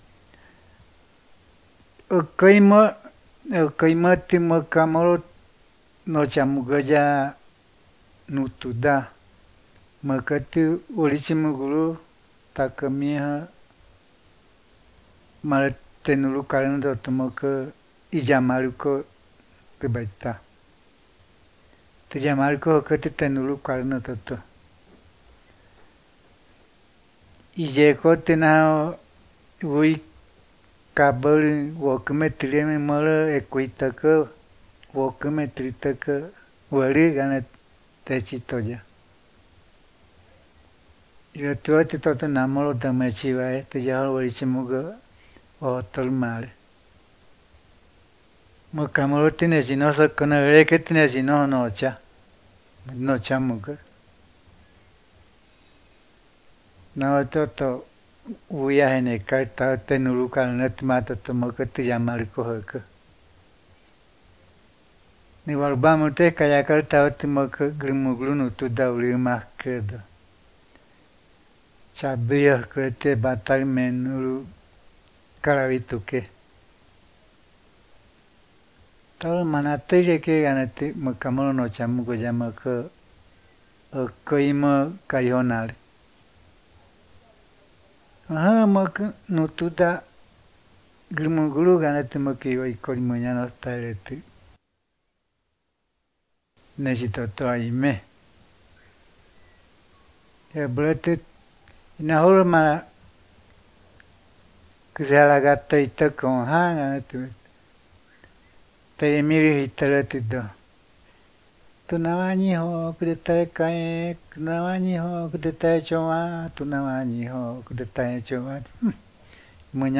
This recording is part of the collection of mythis, stories and songs collected by the Karijona Linguistics Seedbed (Department of Linguistics, Faculty of Human Sciences, Bogotá campus of UNAL) collected from the Karijona people in the Caquetá and Vaupés Rivers between 1985 and 2021 .